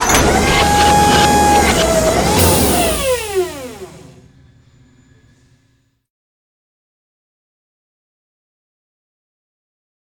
retract.ogg